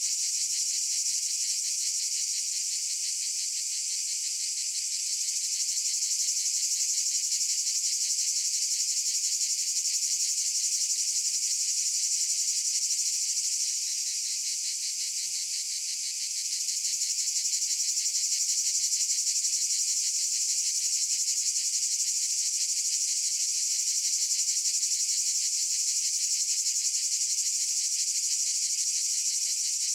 Ambiance_Cicadas_Loop_Stereo.wav